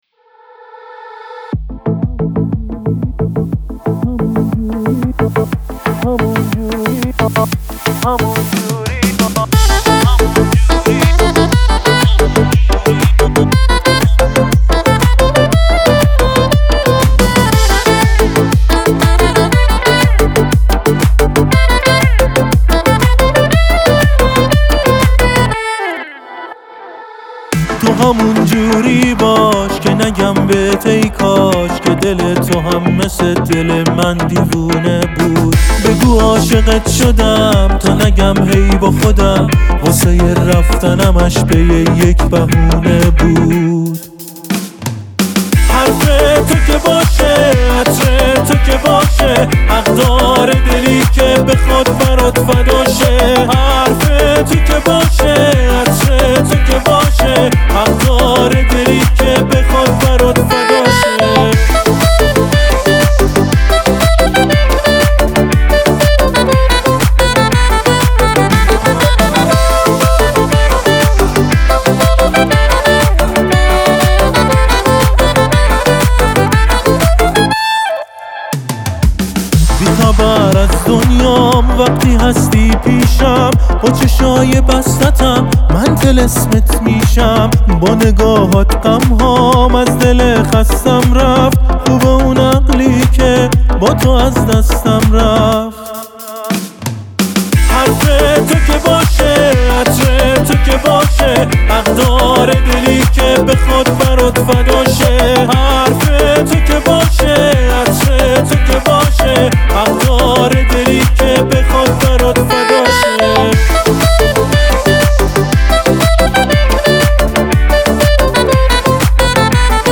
ساکسیفون